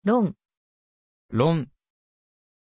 Aussprache von Rayyan
pronunciation-rayyan-ja.mp3